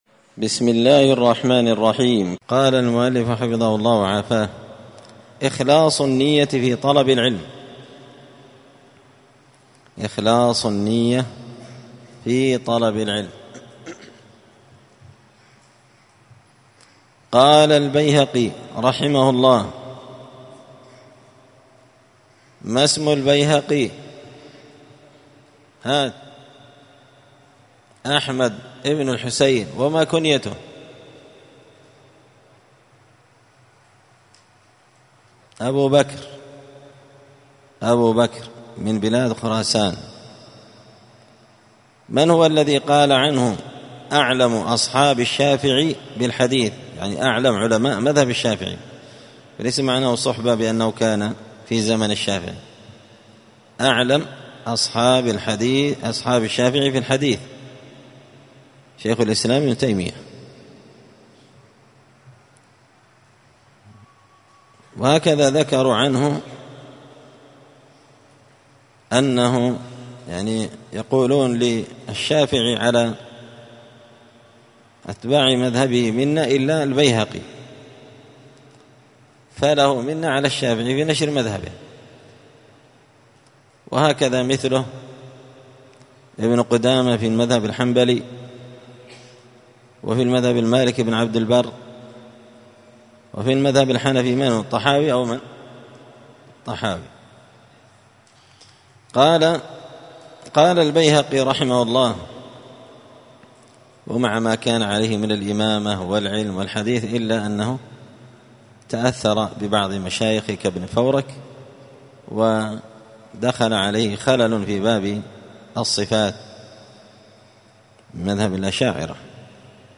دار الحديث السلفية بمسجد الفرقان